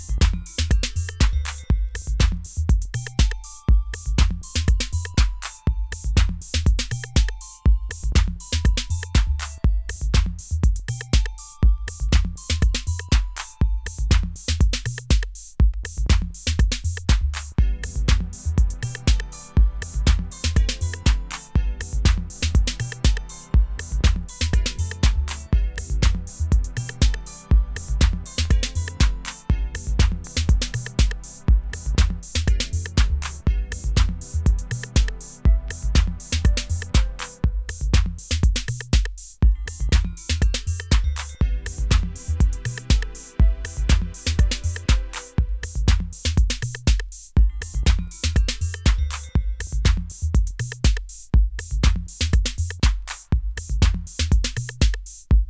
ソウルフルでジャジー、エッジを効かせたハウスは色褪せません。